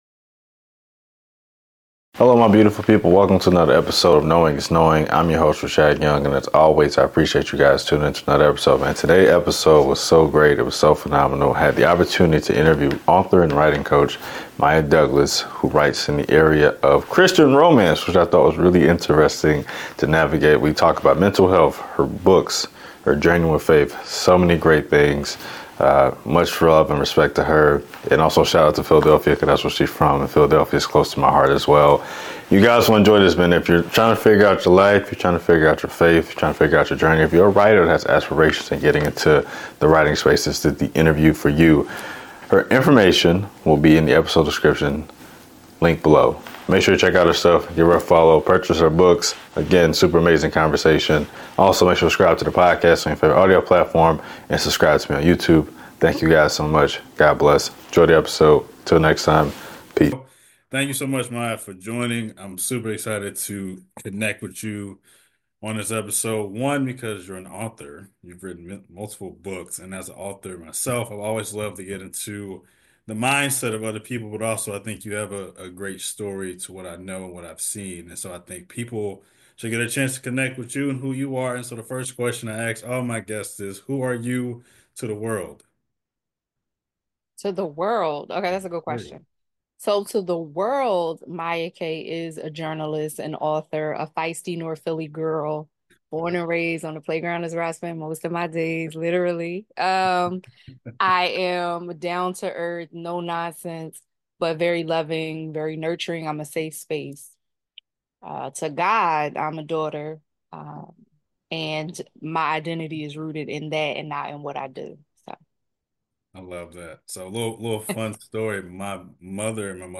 Interview – Knowing Is Knowing Podcast
Get ready for a conversation packed with value, real-world strategies, and maybe even a few laughs!